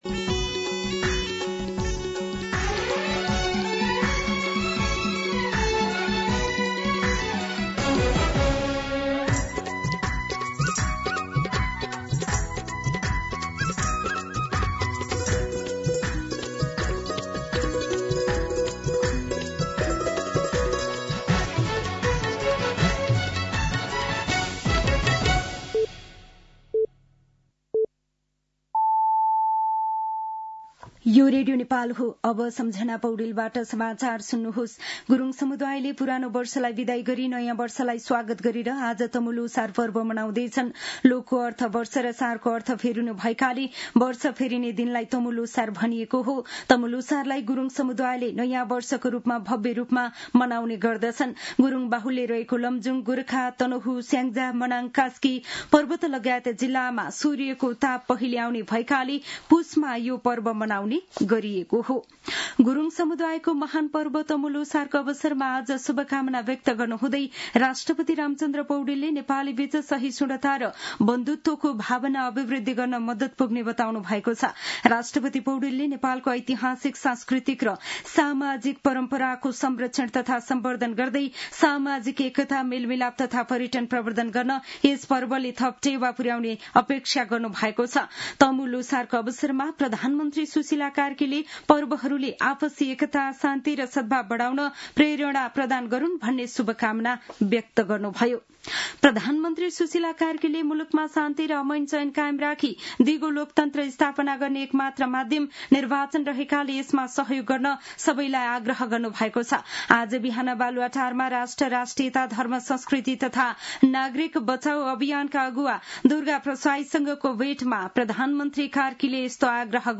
दिउँसो ४ बजेको नेपाली समाचार : १५ पुष , २०८२
4-pm-News-15.mp3